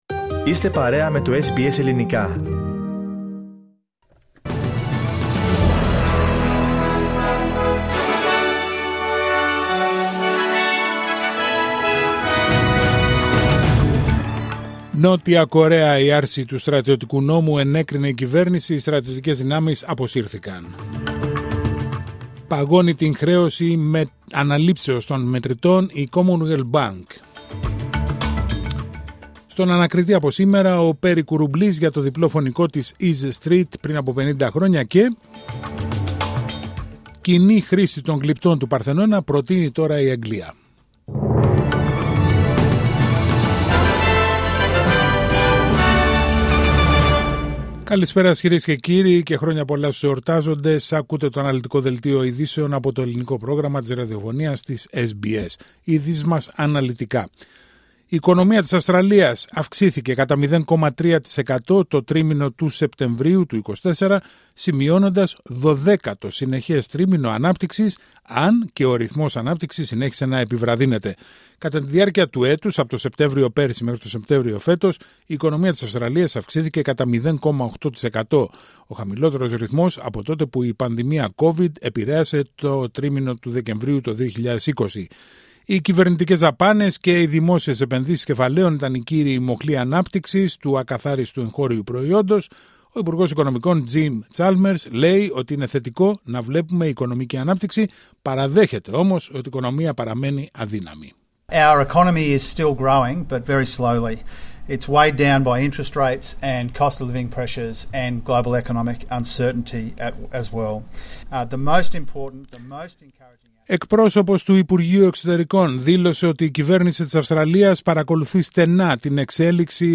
Δελτίο ειδήσεων Τετάρτη 4 Δεκεμβρίου 2024